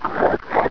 fast_zombie